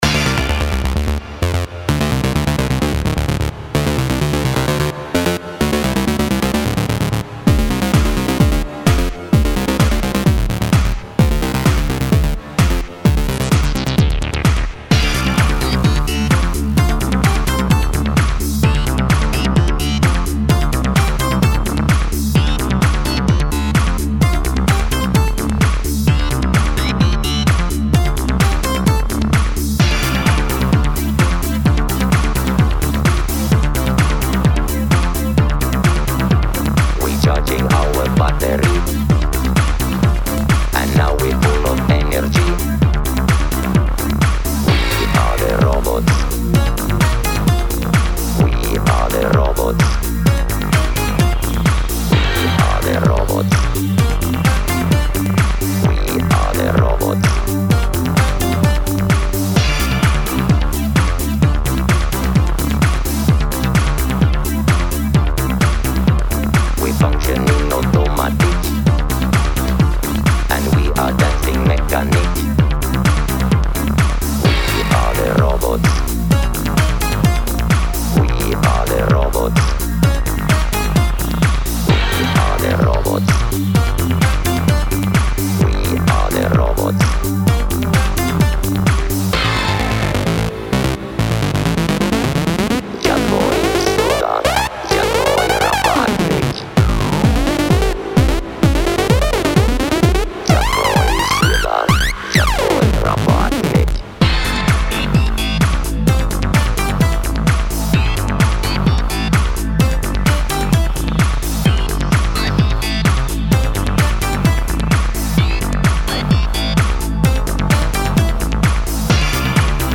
Genre: Dance.